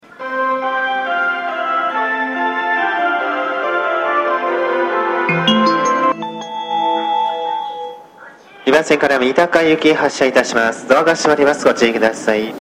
スピー カーはユニペックス小丸型が設置されており音質は比較的良いと思いますね。
発車メロディー余韻切りです。